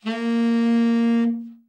Index of /90_sSampleCDs/Best Service - Brass Super Section/TENORSAXSOFT